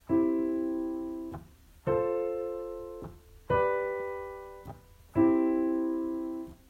Akkordfolgen (Erweiterte Kadenzen) – Songwriting Camp
I-IV-V-I.m4a